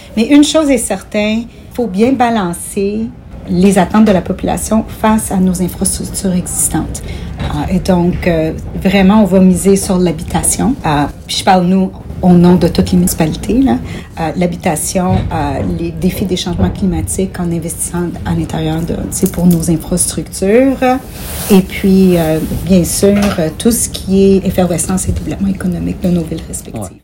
La mairesse de Brossard Doreen Assaad explique être prête aux changements qui s’annoncent au niveau provincial dans les prochains mois